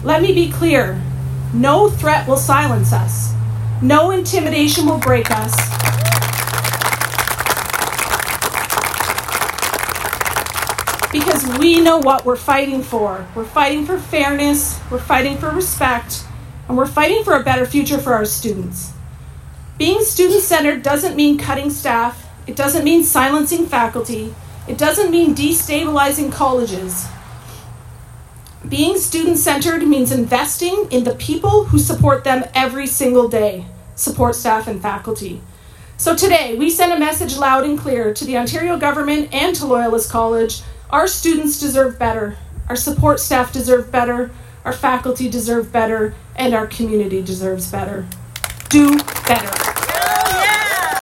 OPSEU held a solidarity rally at the Loyalist College picket line in Belleville this morning.